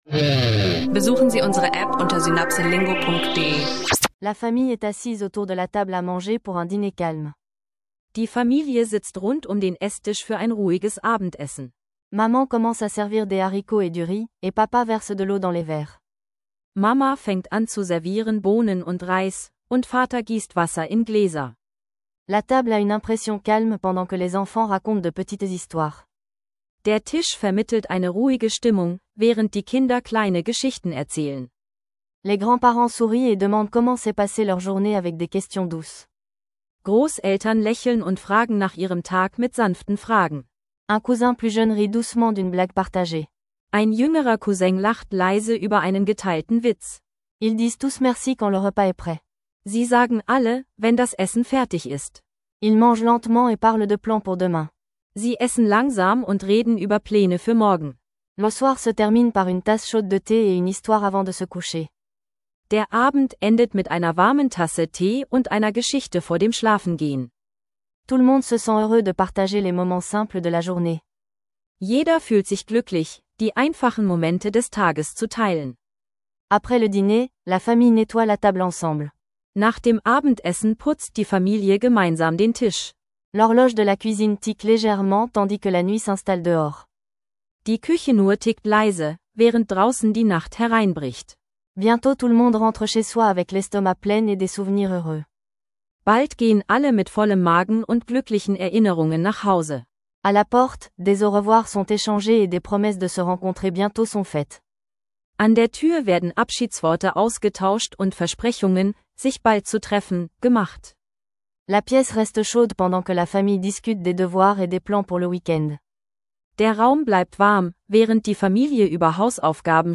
Eine kurze Geschichte über Familienzeit am Esstisch mit Alltagsdialogen – ideal für Anfänger beim Französisch lernen